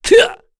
Kain-Vox_Attack3_kr.wav